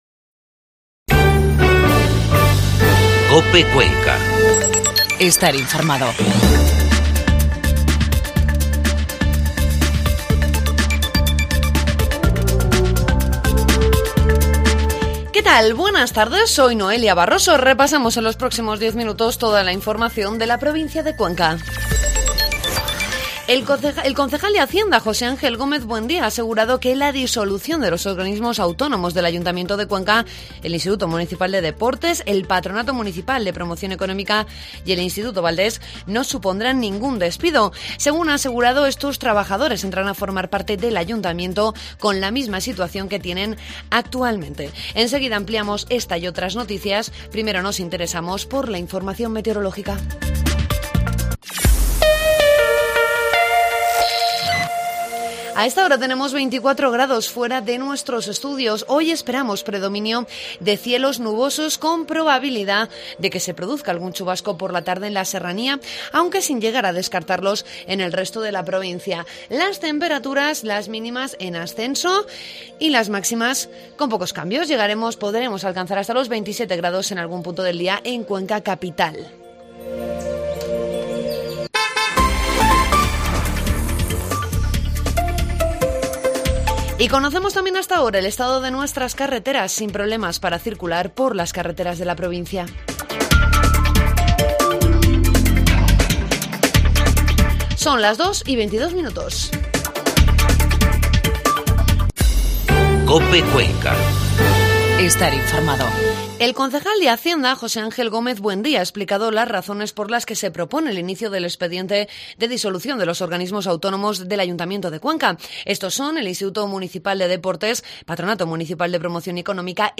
AUDIO: Inforrmativo COPE Cuenca